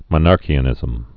(mə-närkē-ə-nĭzəm)